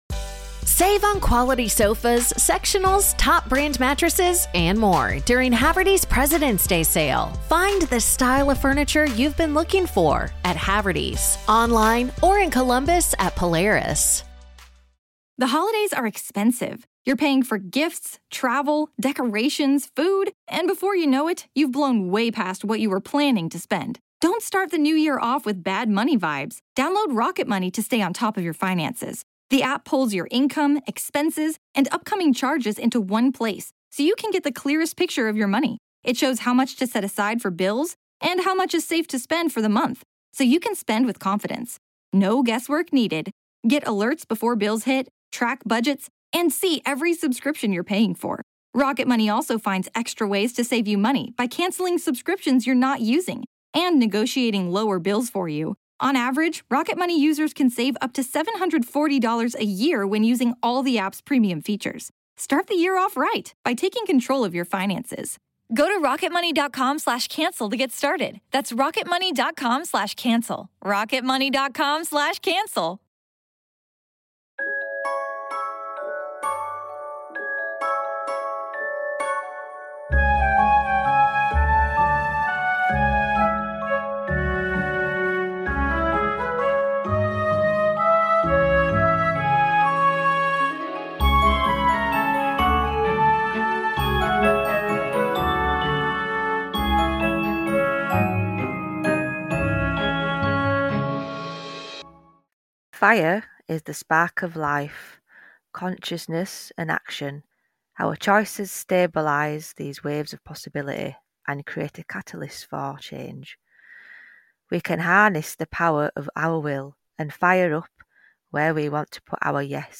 Pour yourself a brew or a glass of mead, come sit with us two northern birds and have a laugh as we go off on tangents.